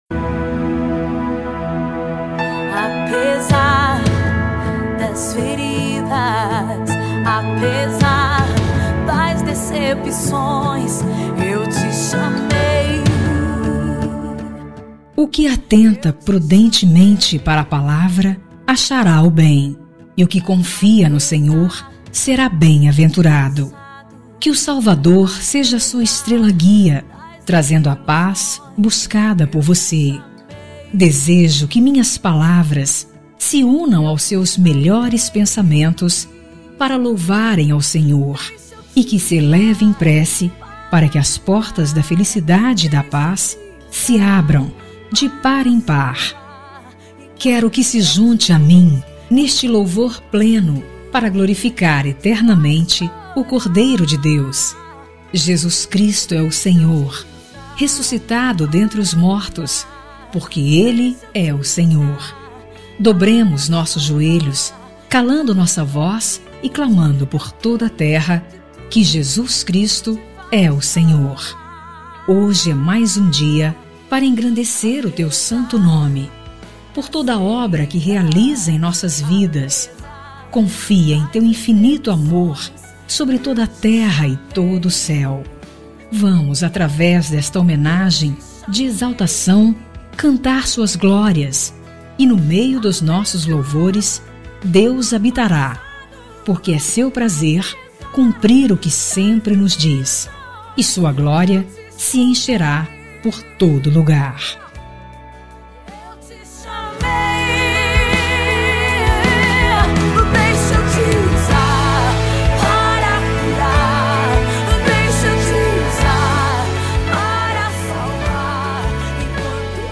NEUTRA EVANGÉLICA
Voz Feminina